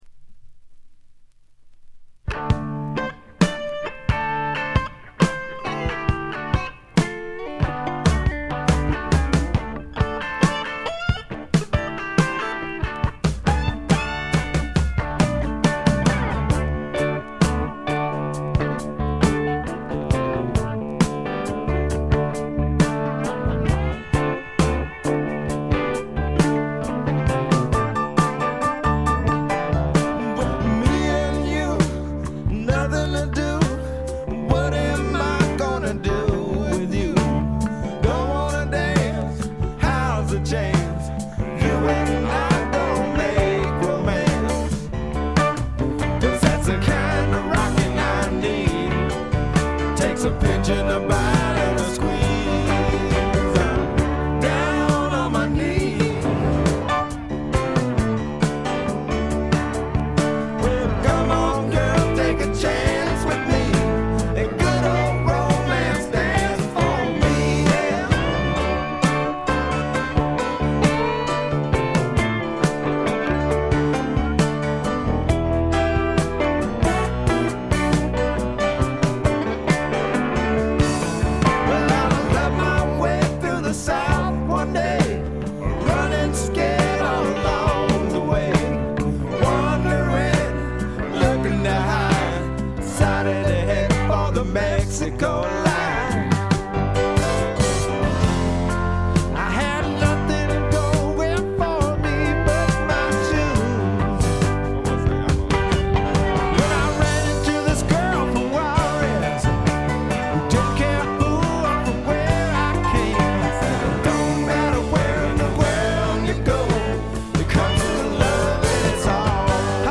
keyboards, synthesizer, vocals